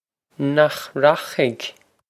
Pronunciation for how to say
Nokh rokh-ig?
This is an approximate phonetic pronunciation of the phrase.